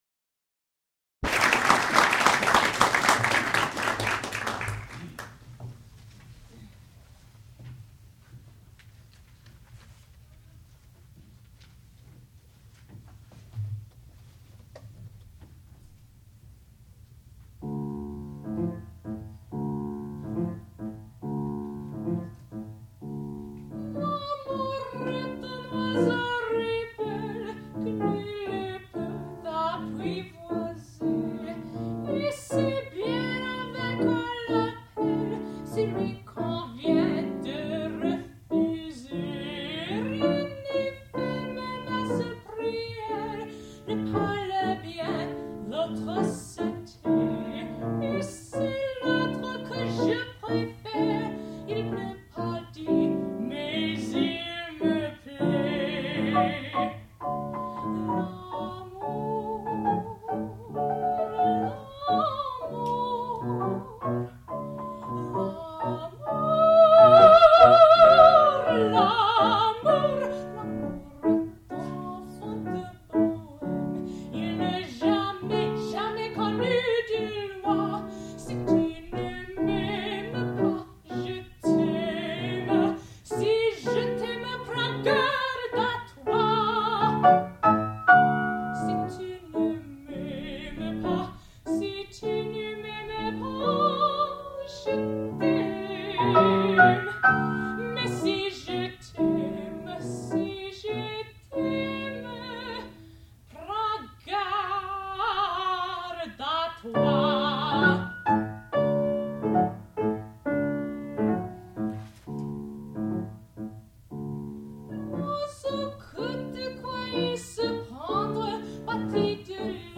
sound recording-musical
classical music
Qualifying Recital
mezzo-soprano